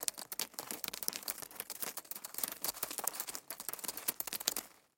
Шорох лап паука по земле